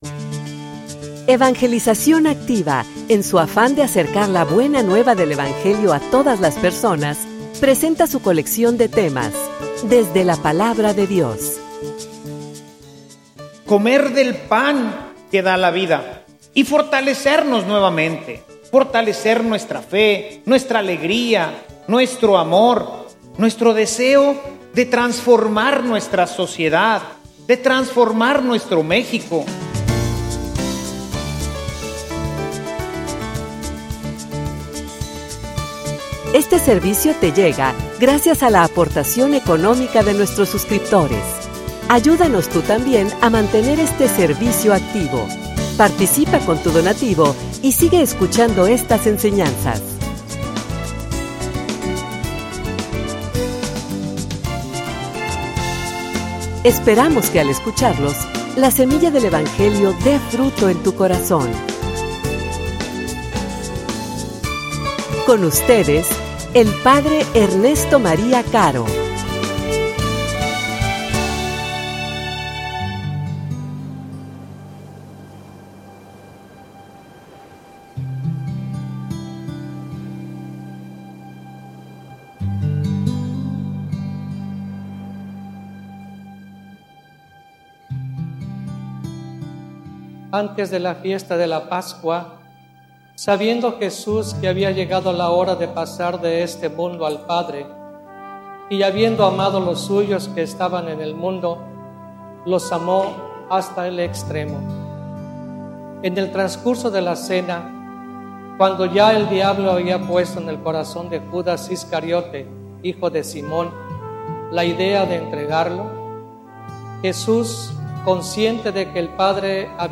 homilia_El_pan_de_la_esperanza.mp3